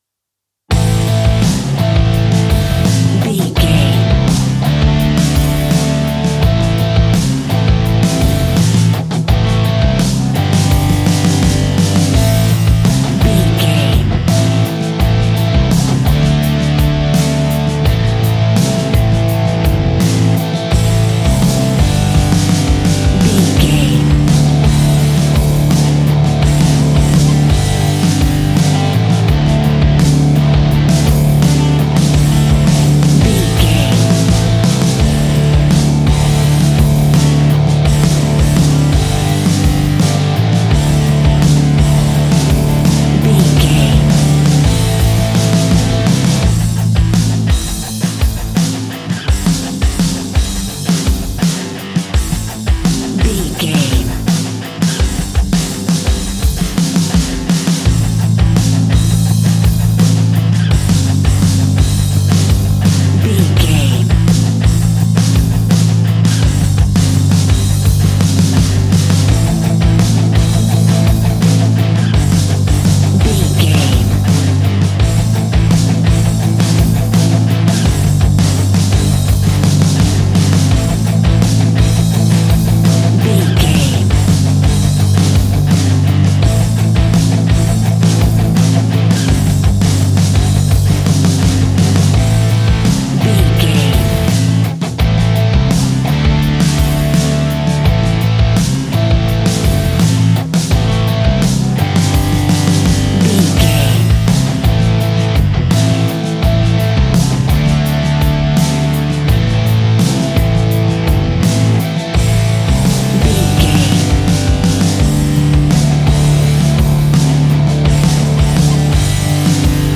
Epic / Action
Dorian
hard rock
blues rock
distortion
rock guitars
Rock Bass
heavy drums
distorted guitars
hammond organ